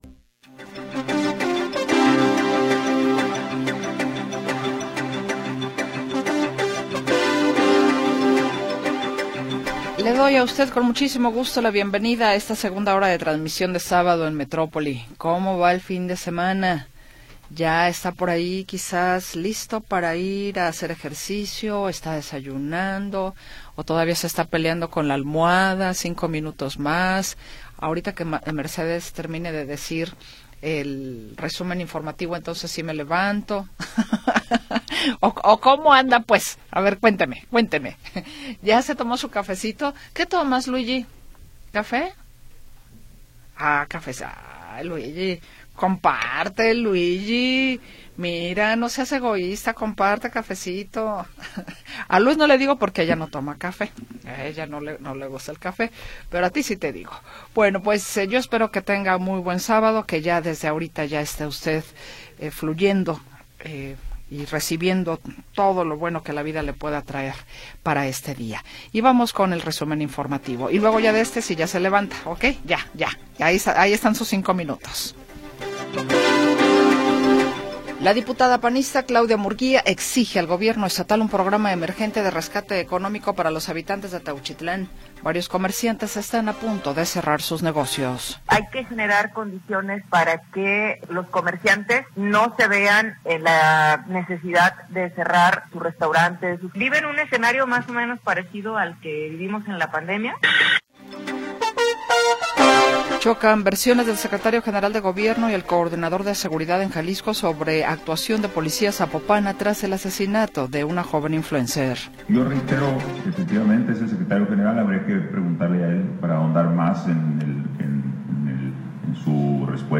Segunda hora del programa transmitido el 17 de Mayo de 2025.